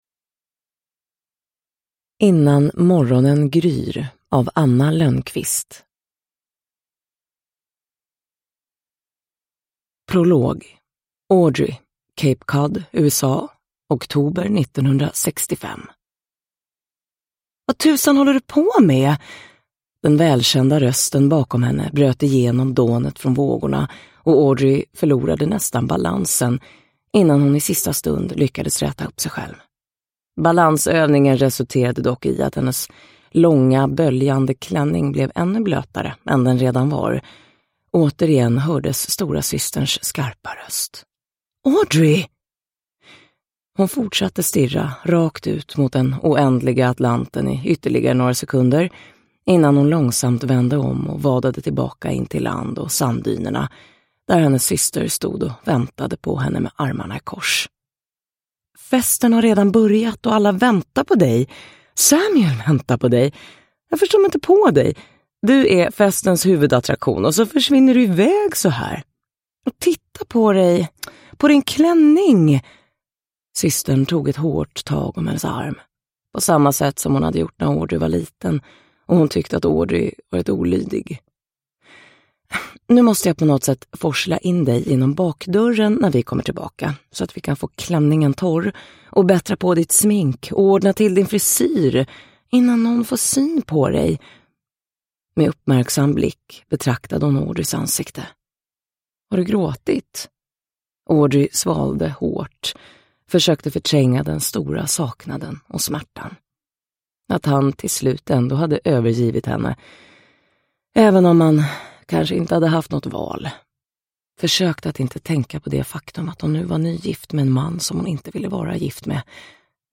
Innan morgonen gryr – Ljudbok – Laddas ner